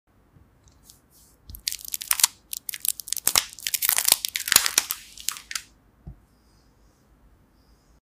No music. No voice. Just sound effects free download